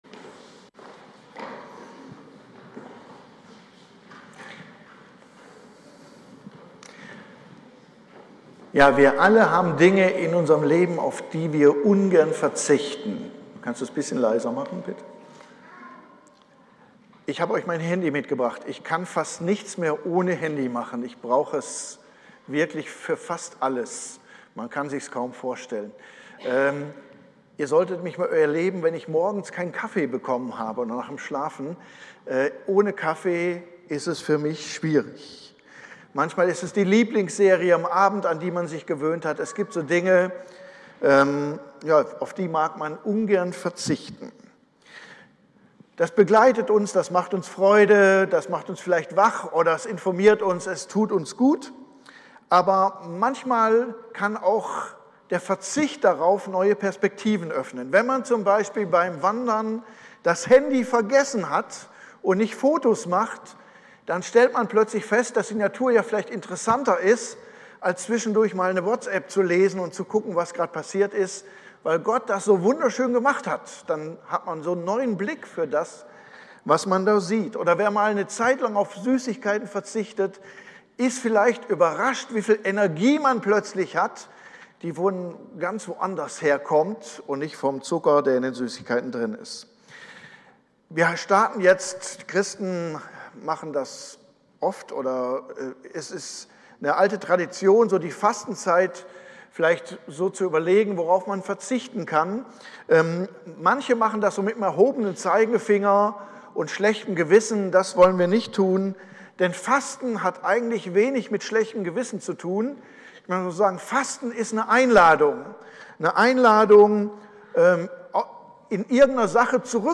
Predigt-am-09.03-online-audio-converter.com_.mp3